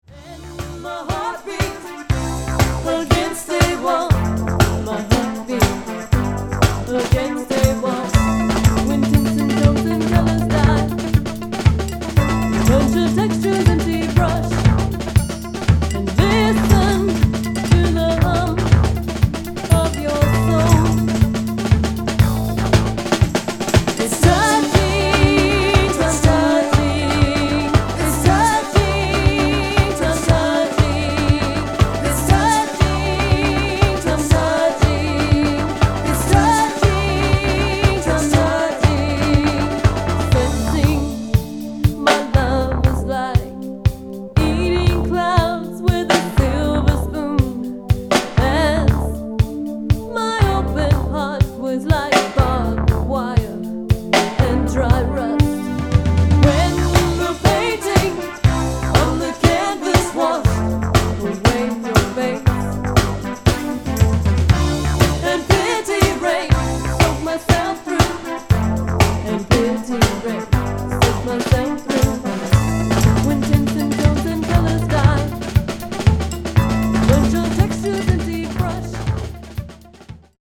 Jazz-Punk is back!
Keyboards & Vocals
Drums & Percussion